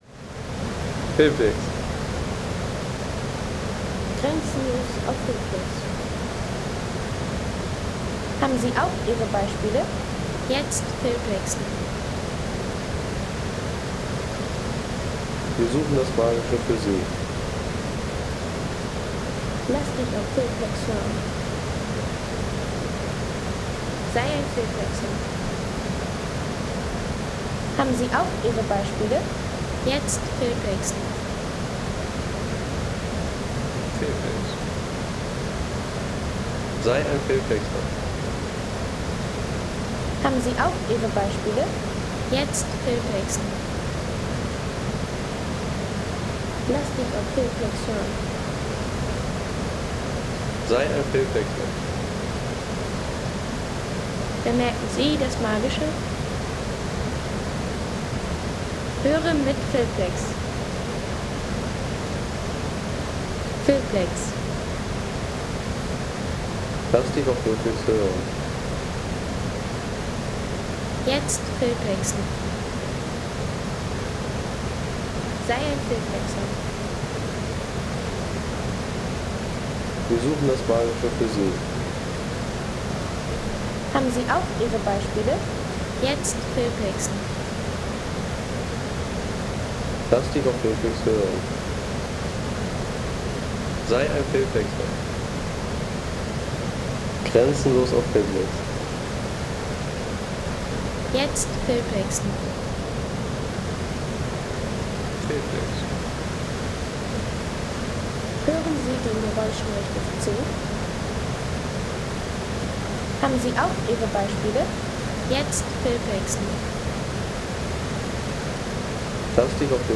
Untertalbach Schladming Bach Soundeffekt für Film und Natur
Untertalbach Schladming Soundeffekt | Frische Gebirgsbach-Atmosphäre
Frische Gebirgsbach-Atmosphäre vom Untertalbach in Schladming mit strömendem Wasser und kühler Bergluft.